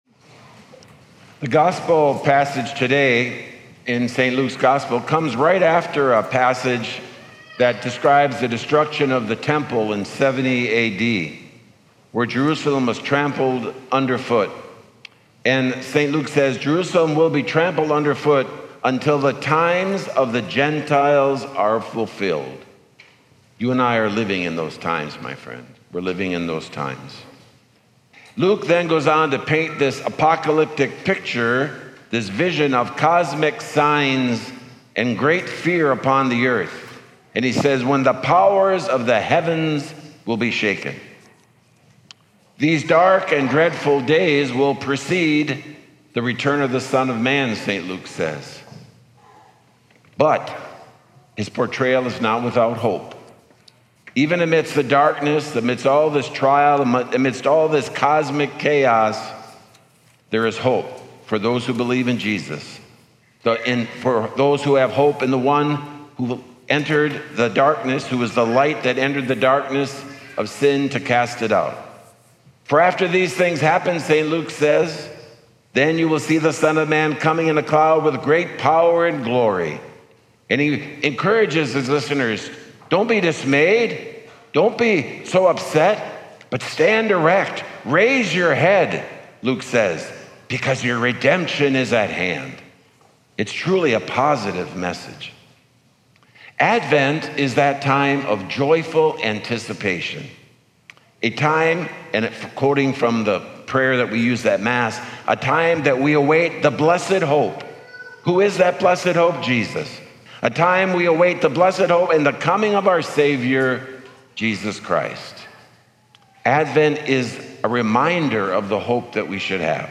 He offered this message during Sunday Morning Mass at Ascension Catholic Church in Overland Park, KS on November 28, 2021.